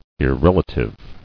[ir·rel·a·tive]